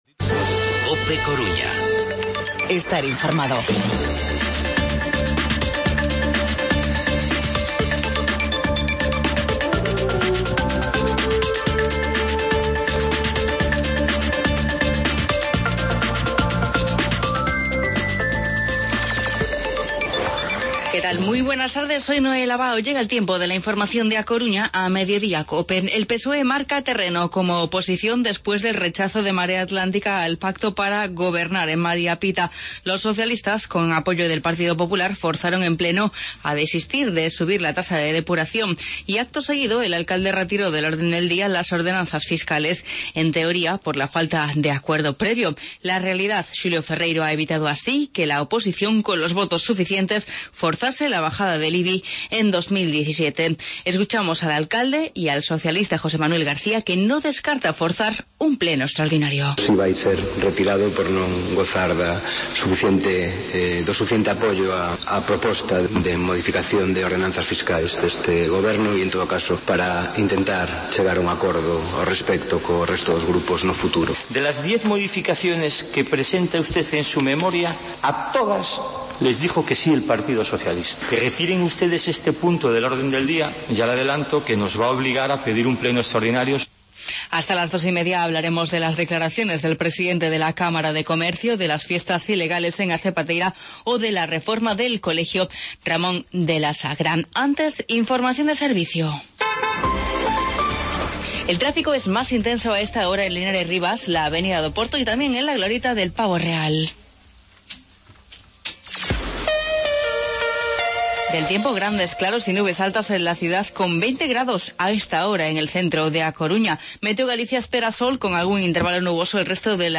Informativo Mediodía COPE Coruña miércoles, 2 de noviembre de 2016